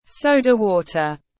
Nước sô-đa tiếng anh gọi là soda water, phiên âm tiếng anh đọc là /ˈsəʊ.də ˌwɔː.tər/
Soda-water-.mp3